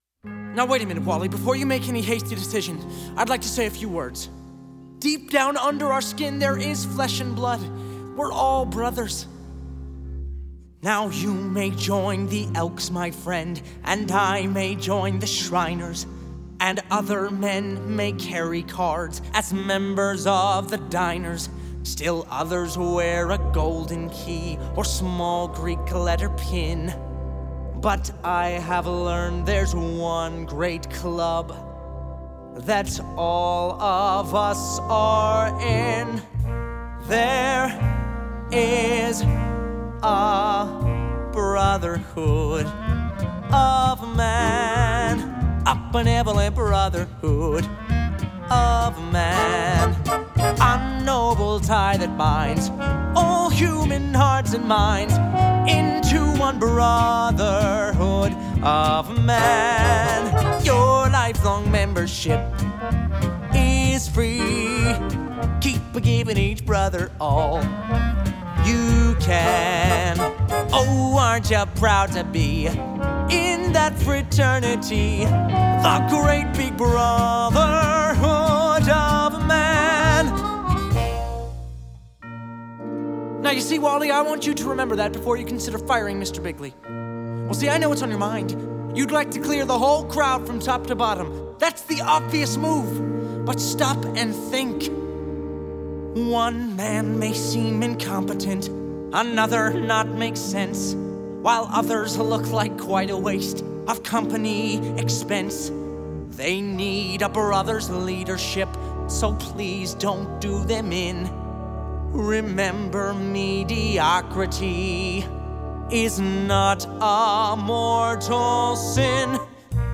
1961   Genre: Musical   Artist